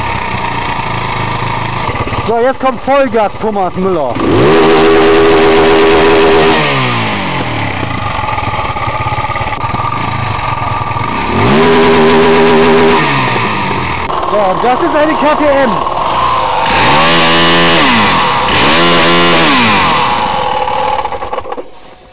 Wenn ich sie warmgefahren habe, und im Leerlauf mal Gas gebe, dann dreht sie nicht sauber aus!
2 Gasstöße meiner Husquvarna und danach das gleiche von meiner 620er KTM (Bj. 94)